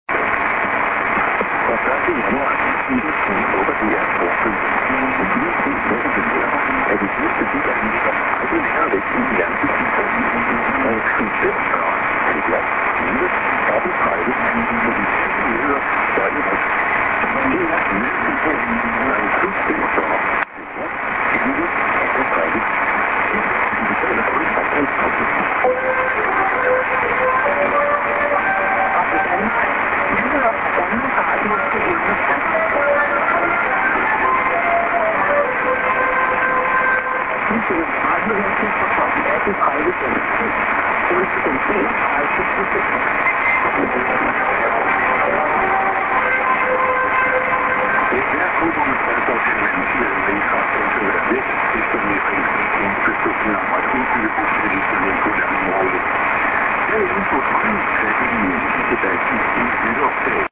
s/on prog:man->30'25":ANN(women:ID)->prog
前日より受信状態は良くありません。